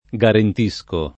garent&Sko], ‑sci — lett. guarentire: guarentisco [gUarent&Sko], ‑sci — antiq. guarantire: guarantisco [